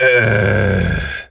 gorilla_growl_deep_05.wav